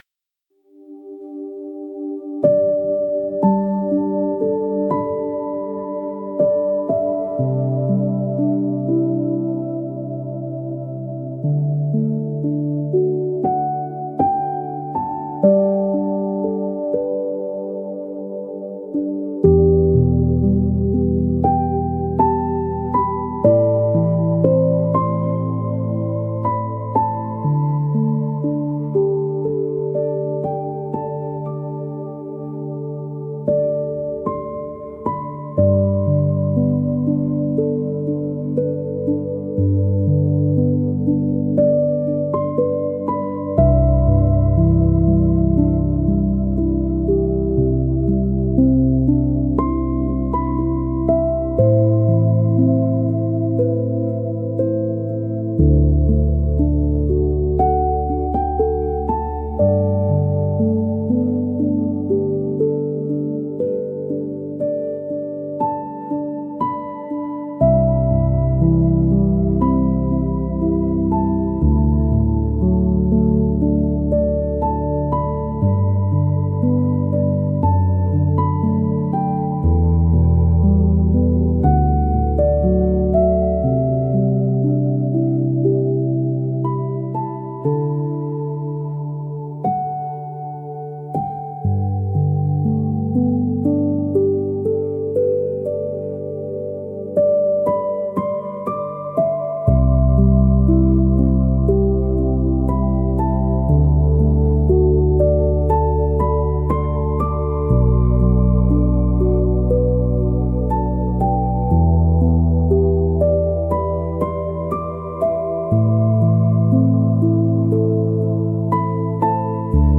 29A-Forest-Theta-Lullaby.mp3